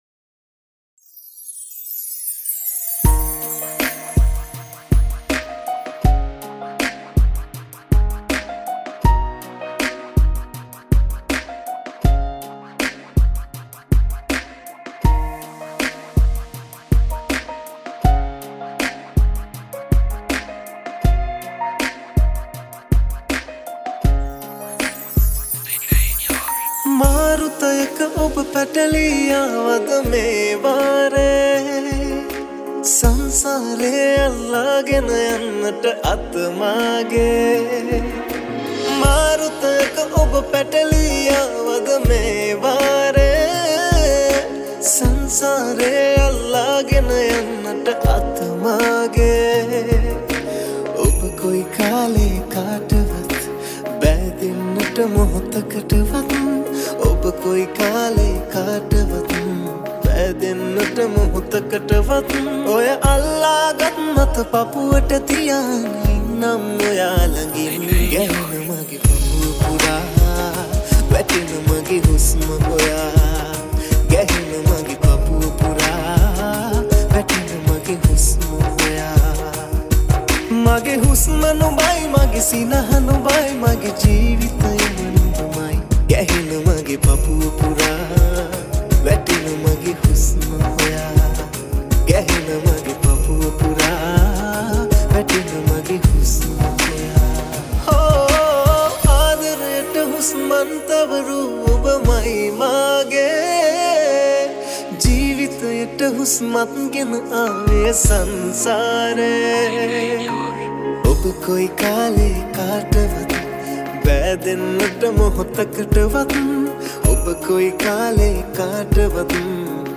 Hip Hop Mix
80 Bpm Mp3 Download